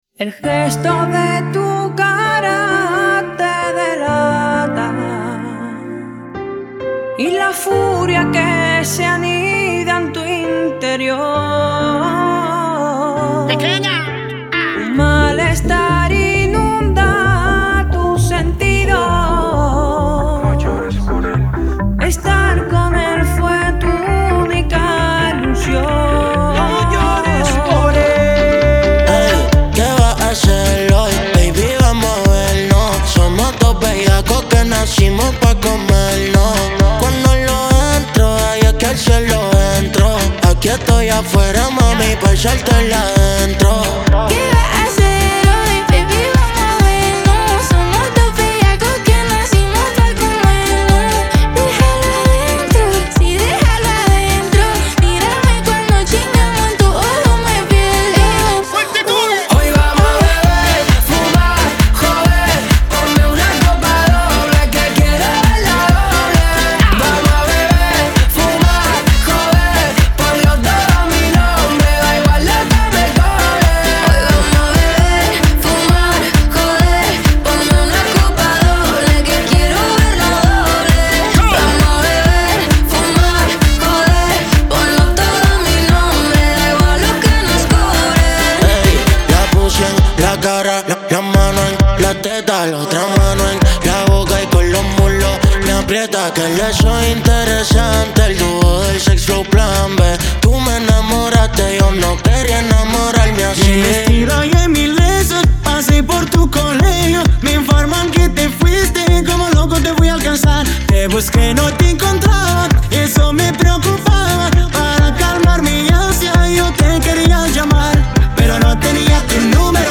Acapella